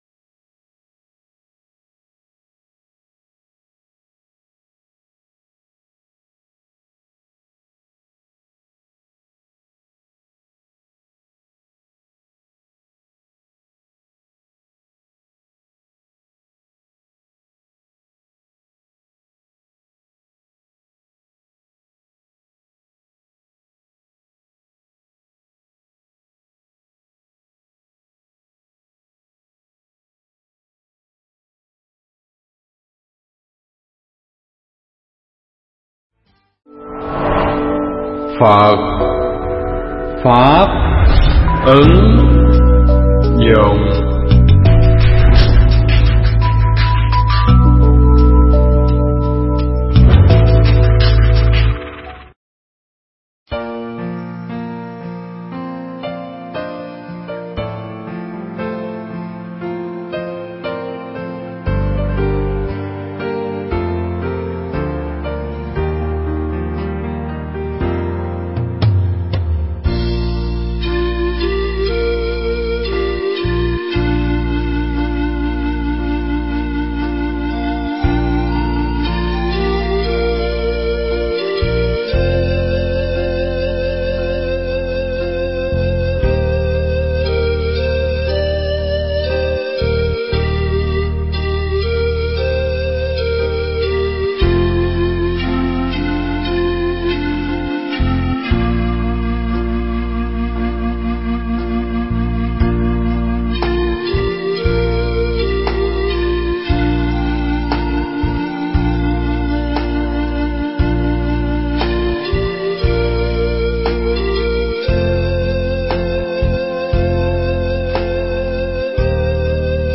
Thuyết pháp
thuyết pháp tại chùa Bửu Quang.